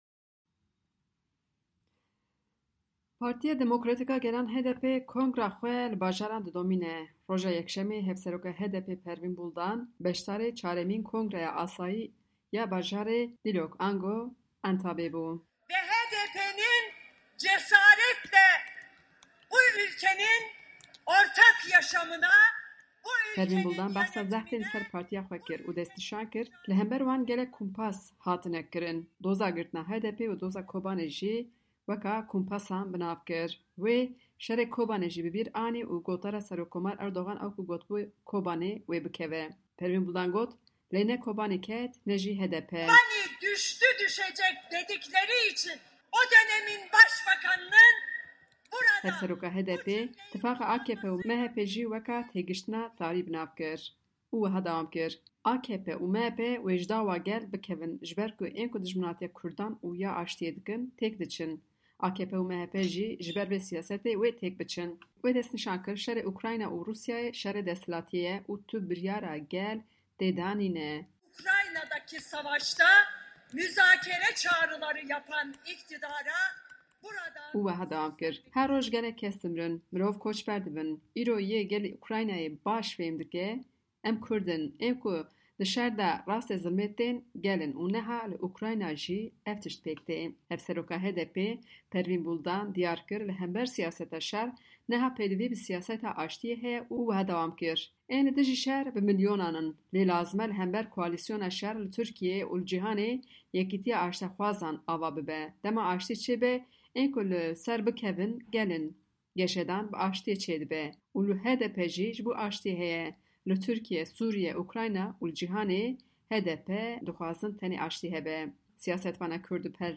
Hevseroka HDPê îro Yekşemê li bajrê Entabê (Dîlok) beşdarî kongreya partîya xwe bû û li wir helwesta hikumeta AKPê li hember şerê Rûsya û Ukraynayê bi durûtî da zanîn.